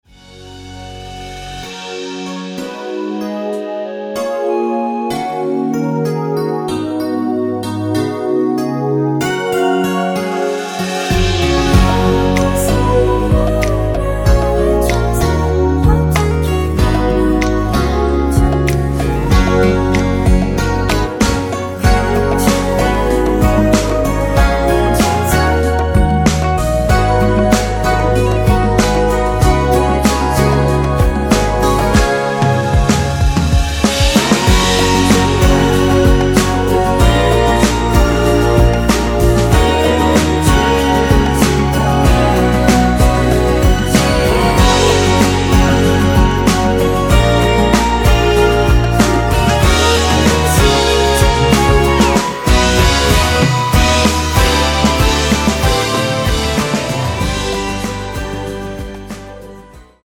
원키에서(+2)올린 멜로디와 코러스 포함된 MR입니다.(미리듣기 참고)
F#
앞부분30초, 뒷부분30초씩 편집해서 올려 드리고 있습니다.